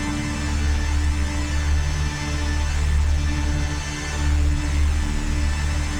DD_LoopDrone3-D.wav